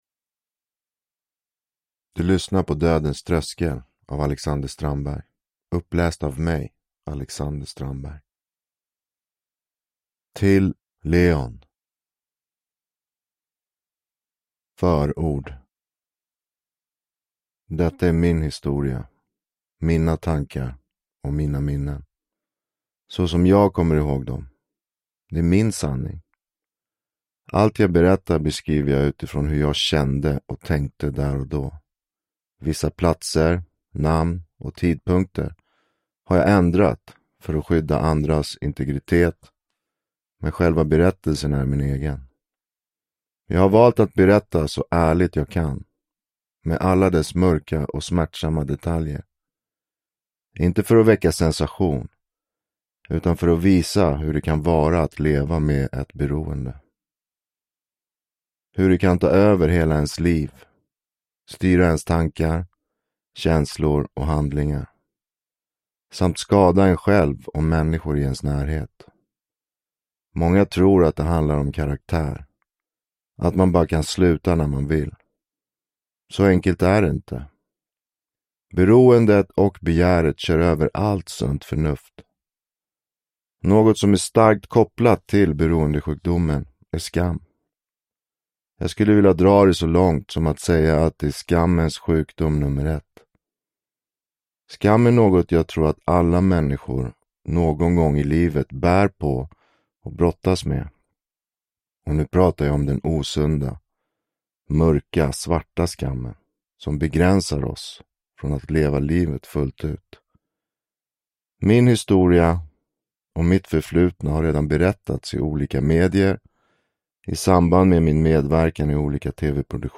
Dödens tröskel – Ljudbok